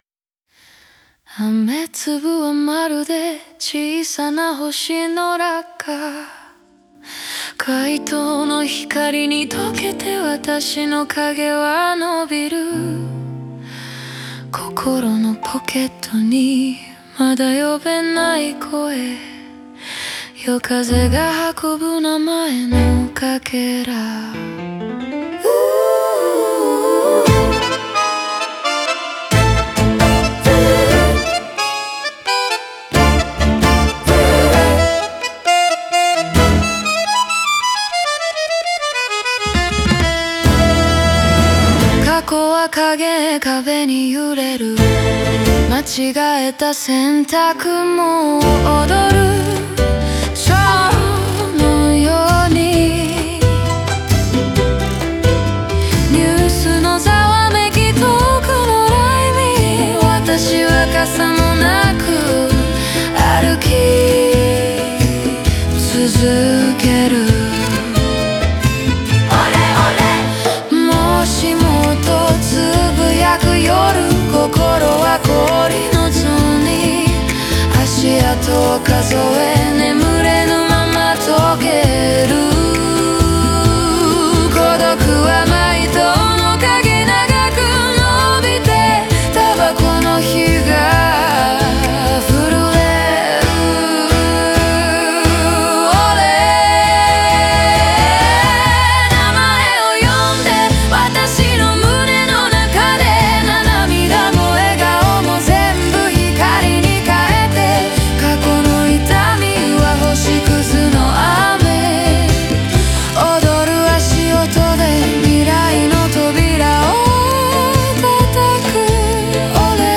オリジナル曲♪
タンゴ調のリズムとコーラスが緊張感と躍動感を生み、街のざわめきや足音が臨場感を添える。